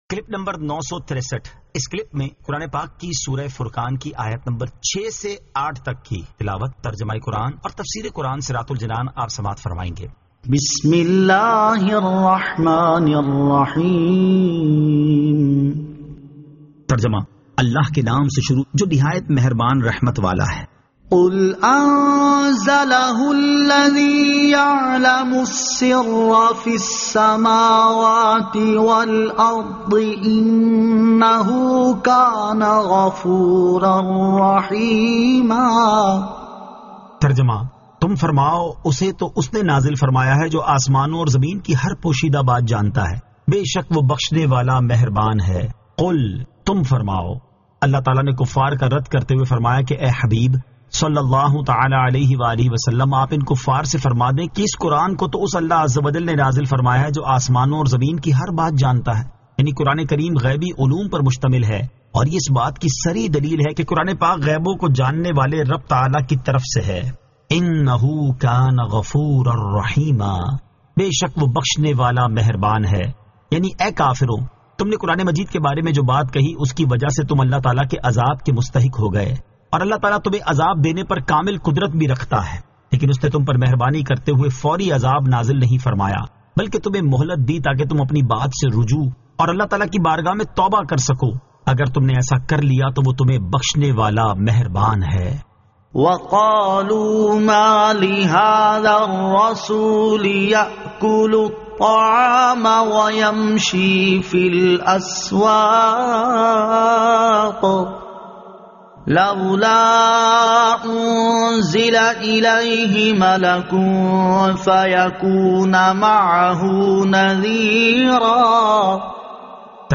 Surah Al-Furqan 06 To 08 Tilawat , Tarjama , Tafseer